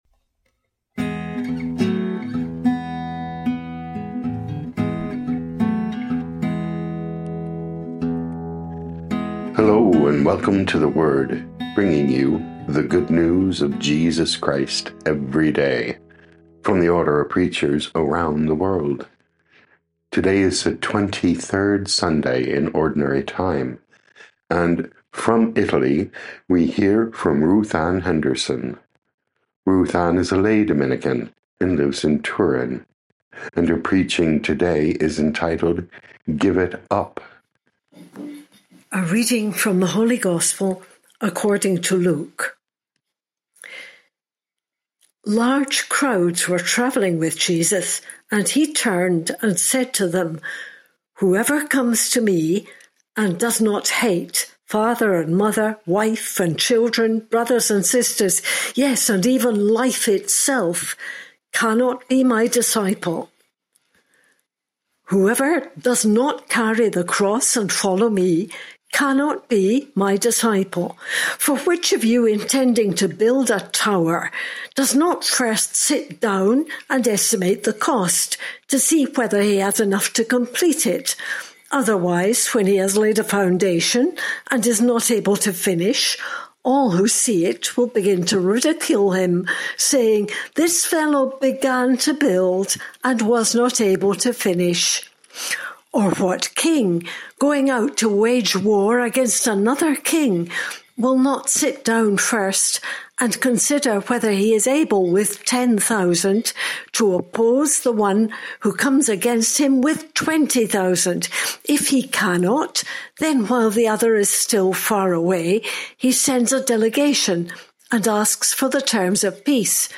O.P. Preaching